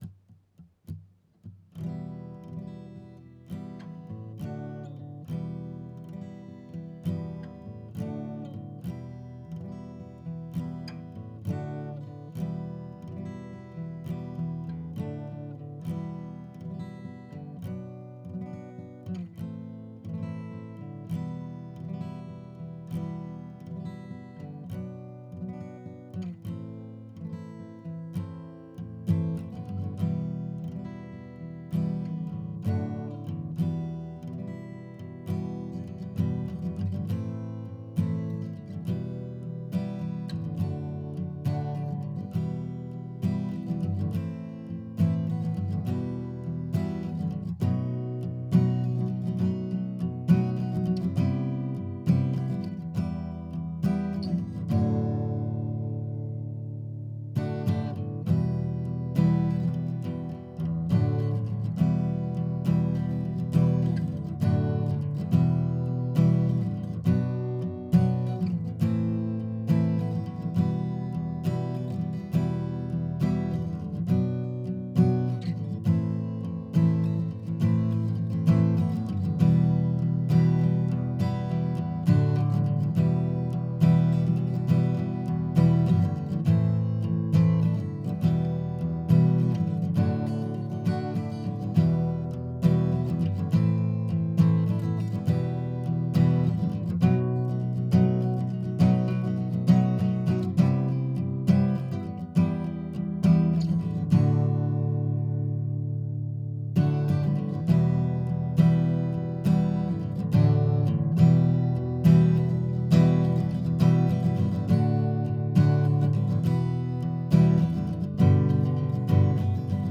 Toward Tomorrow - Guitar - Mono.wav